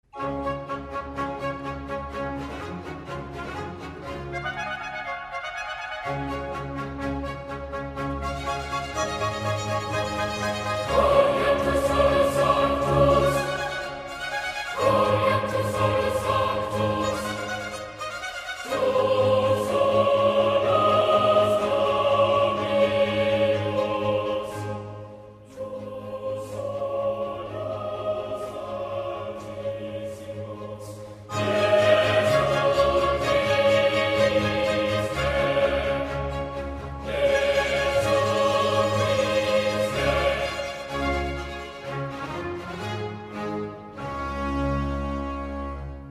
• Качество: 128, Stereo
красивые
скрипка
инструментальные
хор
оркестр
классическая музыка
хоровое пение